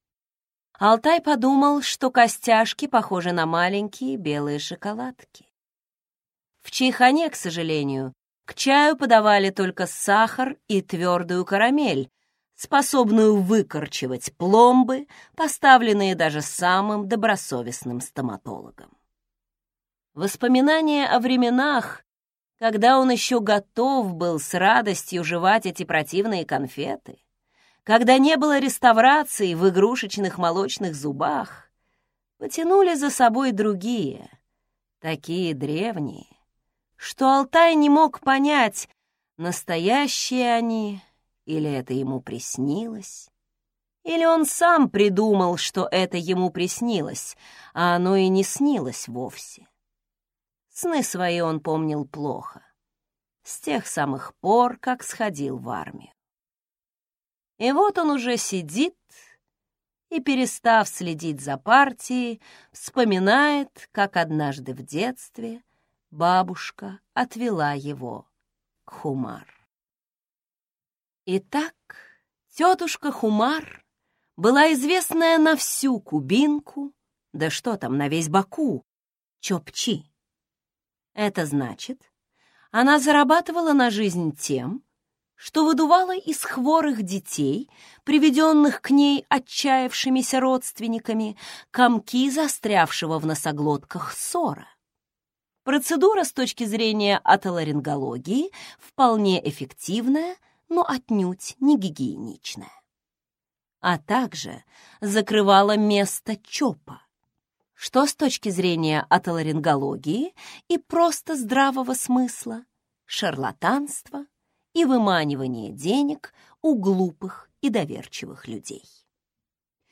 Аудиокнига Сны Ocimum Basilicum | Библиотека аудиокниг